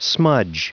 Prononciation du mot smudge en anglais (fichier audio)
Prononciation du mot : smudge